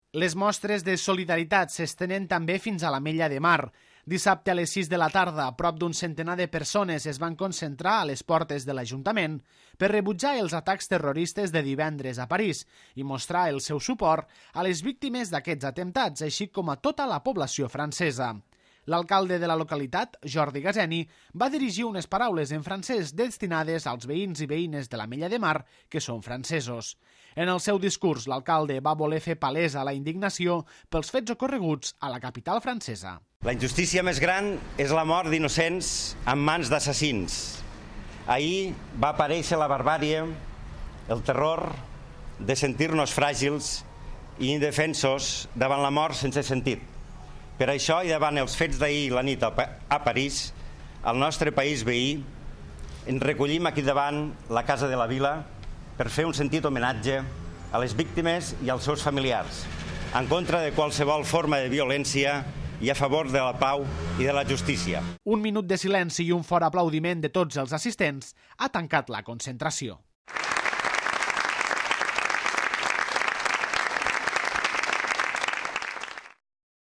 Dissabte a les 18.00 h de la tarda, prop d'un centenar de persones es van concentrar a les portes de l'Ajuntament per rebutjar els atacs terroristes de divendres a París i mostrar el seu suport a les víctimes d'aquests atemptats així com a tota la població francesa. L'alcalde de la localitat, Jordi Gaseni, va dirigir unes paraules en francès destinades als veïns i veïnes de l'Ametlla de Mar que són francesos. En el seu discurs, l'alcalde va voler fer palesa la indignació pels fets ocorreguts a la capital francesa. Un minut de silenci i un fort aplaudiment de tots els assistents ha tancat la concentració.